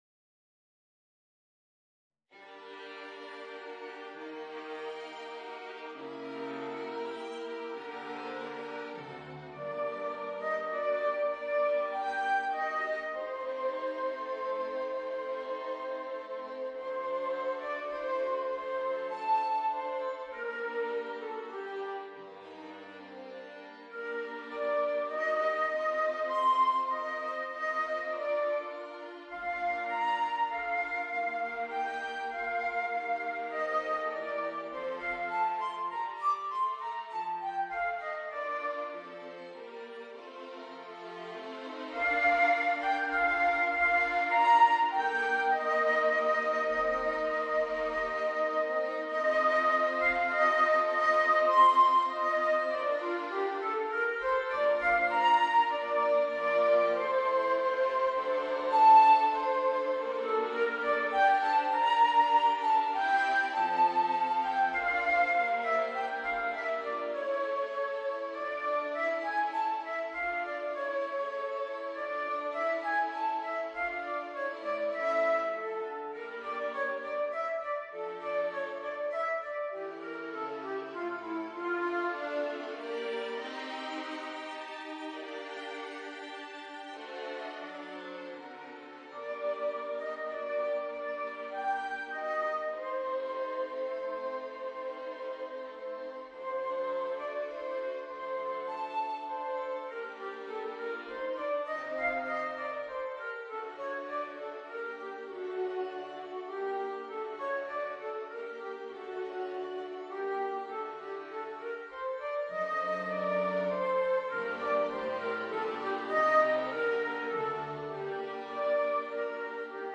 Voicing: Violin and String Quartet